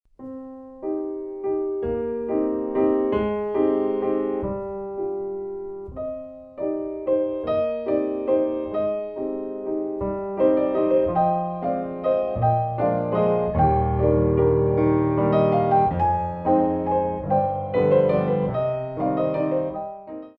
Ronds de Jambe en L'air